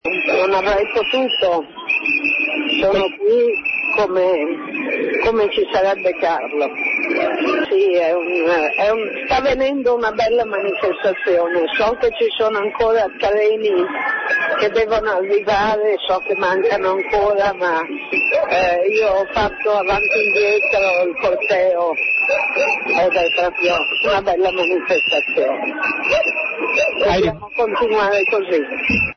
Il saluto di Heidi Giuliani dal corteo di Torino